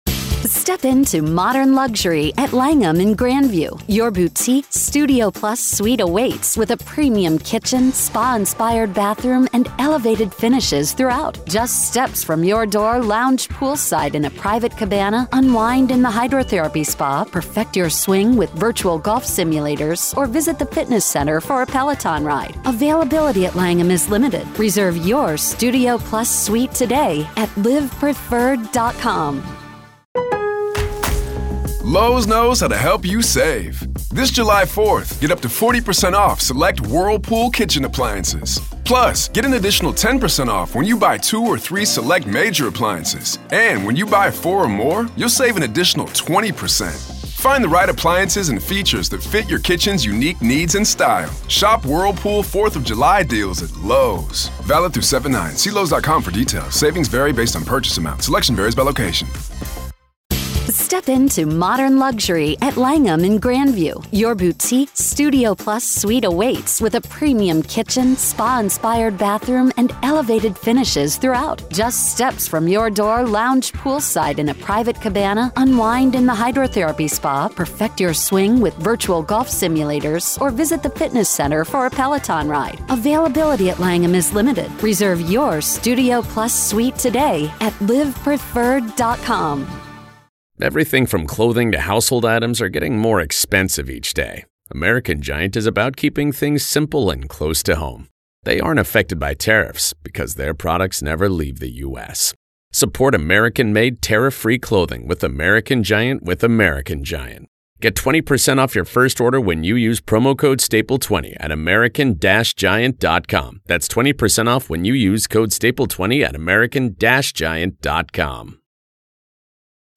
True Crime Today | Daily True Crime News & Interviews / Could A Brain Scan Of Robert Telles Predict His Alleged Murderous Behavior?